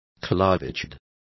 Complete with pronunciation of the translation of clavichords.